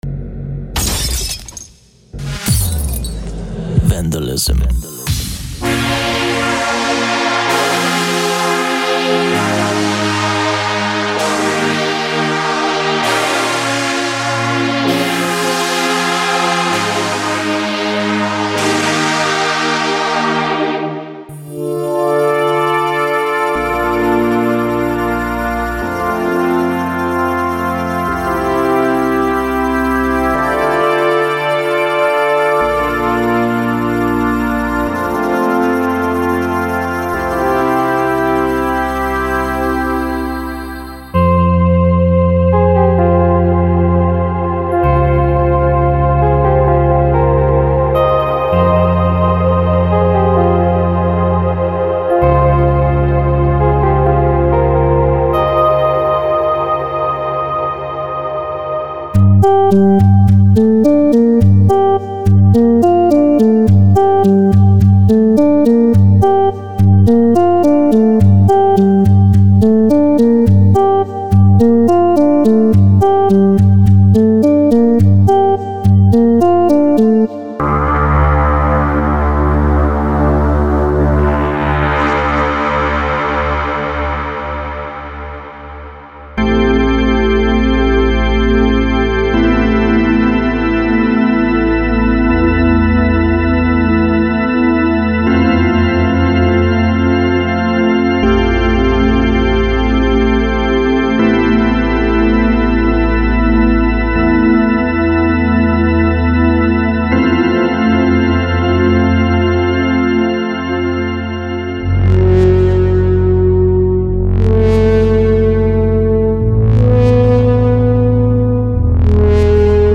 Ambient Cinematic / FX Melodic Techno Progressive House Trance
These sounds are evolving with added movement. This set explores all things ambient, including massive cinematic pads, lush evolving atmospheres and soundscapes, deep haunting plucks, keys, cellos and shimmering tones, textures and atmospheric effects!